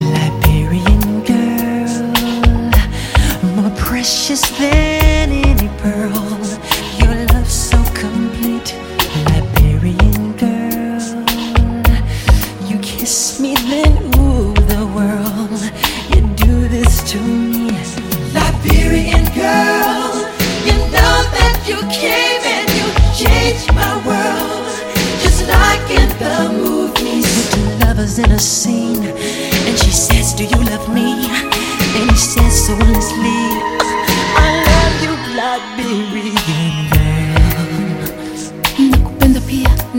романтические , поп
rnb